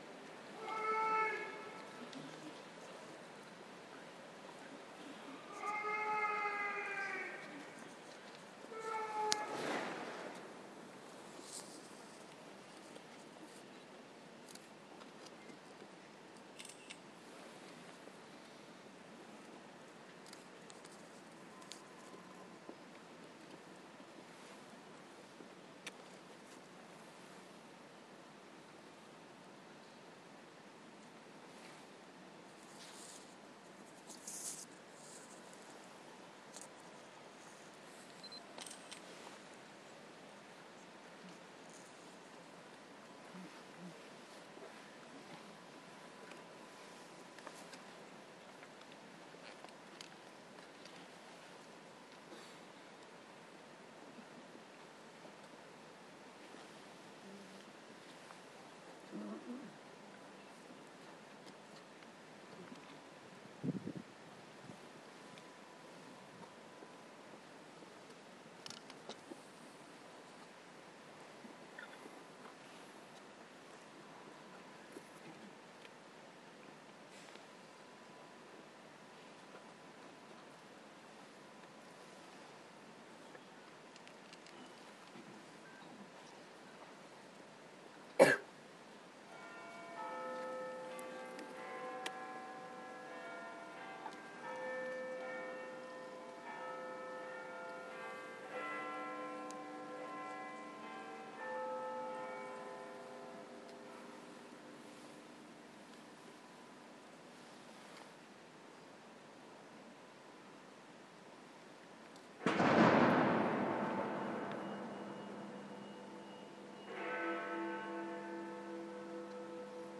Two minutes of silence at the Cenotaph
A recording taken from within the crowd adjacent to MOD Main Building, as the two minute silence is marked at the National Service of Remembrance at the Cenotaph.